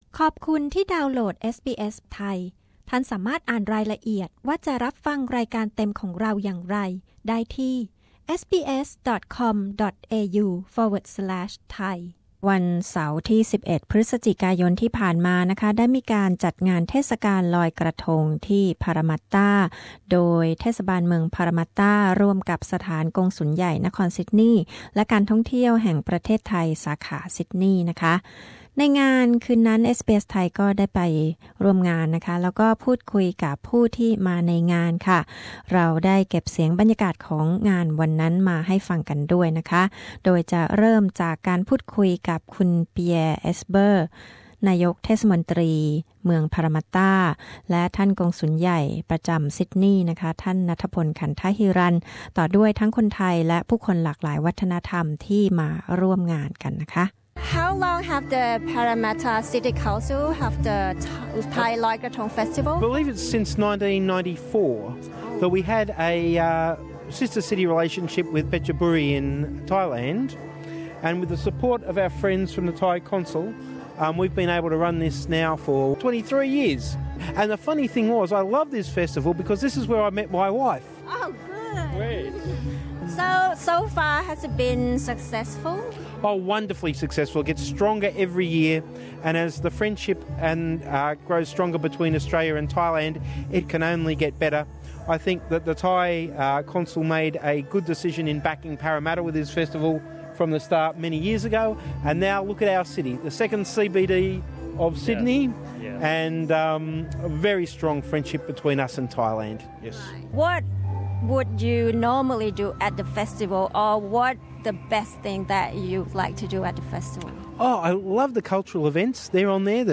เอสบีเอส ไทย พูดคุยกับคนไทยและผู้คนหลากวัฒนธรรม ที่มาร่วมงานลอยกระทง พารามัตตา เมื่อวันเสาร์ ที่ 11 พฤศจิกายน ที่ผ่านมา